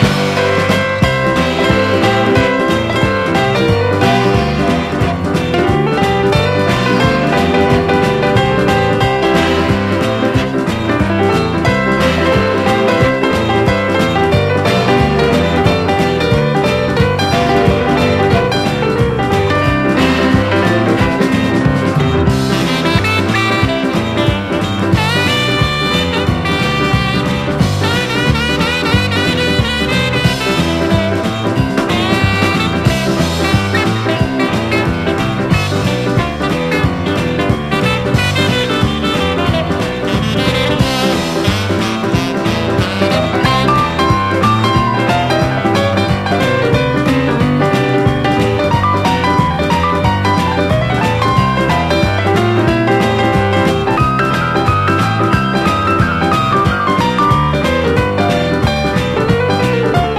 ホラー・ポップコーン＆パーティー・ロカビリー人気曲を収録！ カナダのカントリー/ポップ・デュオ！